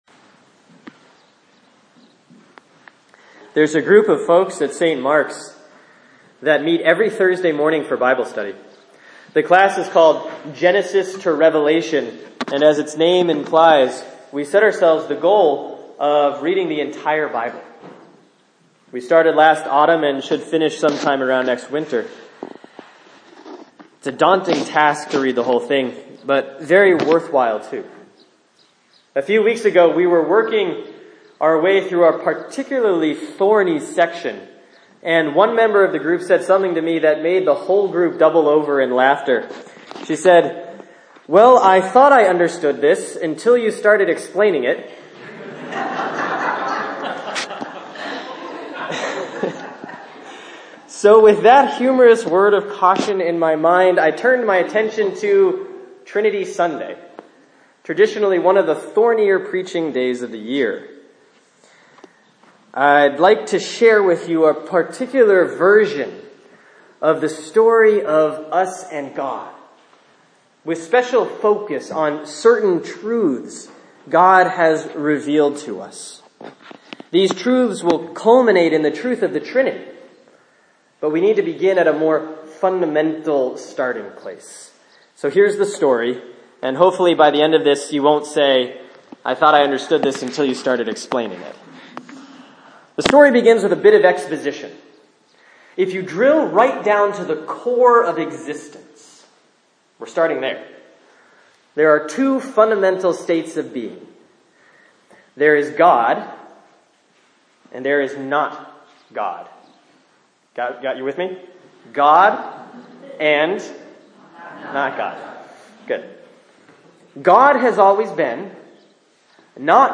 Sermon for Sunday, May 22, 2016 || Trinity Sunday C || John 16:12-15